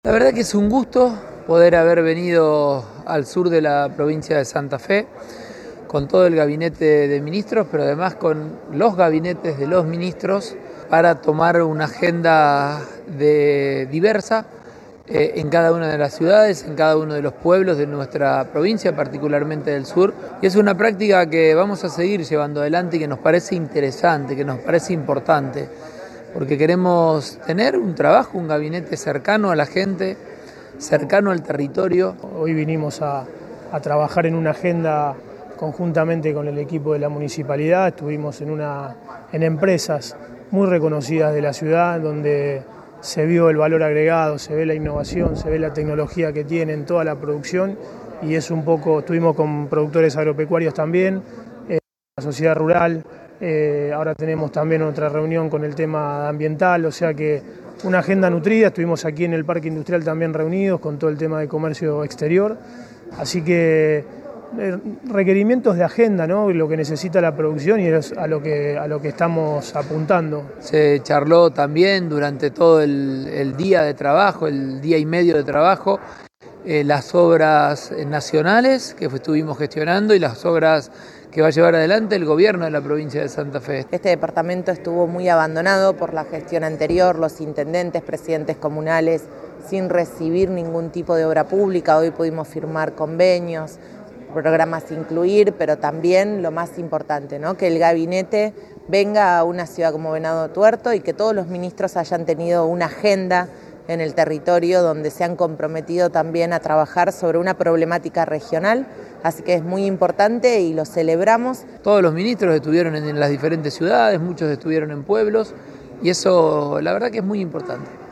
Declaraciones Pullaro